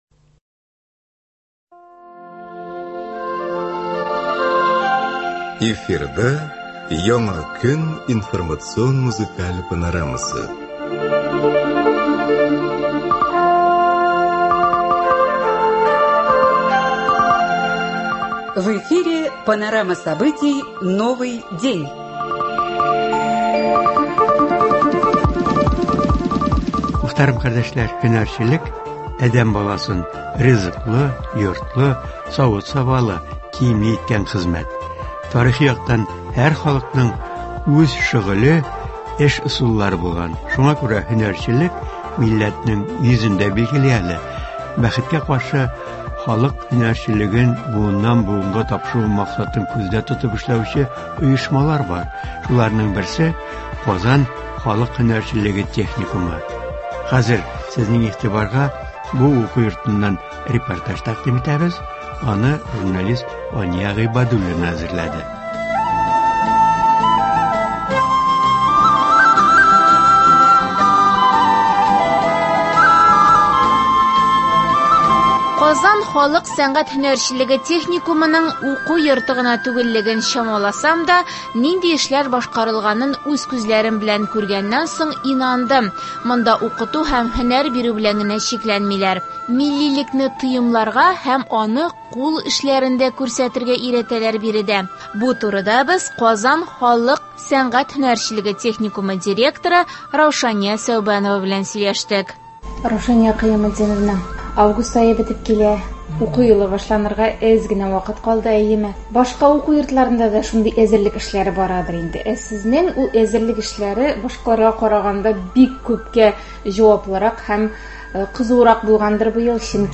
Шуларның берсе — Казан халык һөнәрчелеге техникумы. Хәзер сезнең игътибарга бу уку йортыннан репортаж тәкъдим итәбез.